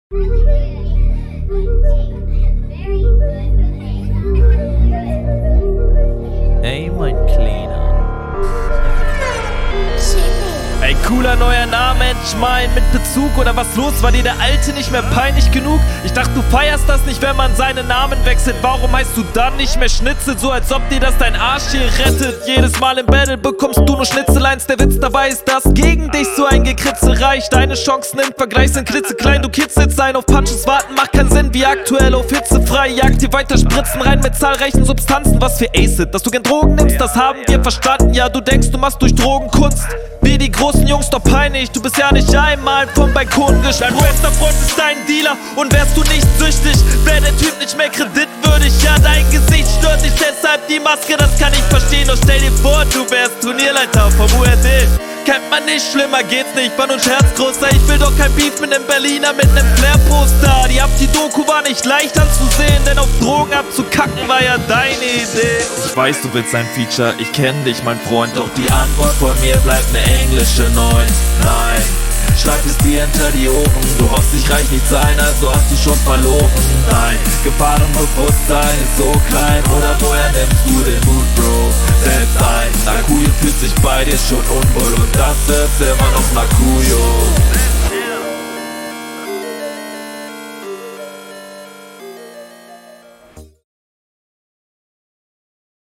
Stabil geflowt, was aber wirklich positiv hervorsticht ist die Reimtechnik, Kompliment dafür, Gegnerbezug ist auch …
Der Flow überzeugt durch ästhetische Spielerein mit dem Metrum, einer abwechslungsreichen Pausensetzung, kurzen Shuffles, einer …
Hast einen anspruchsvollen Flow auf nem speziellen Beat sehr locker runtergerappt. Einzig die Betonung von …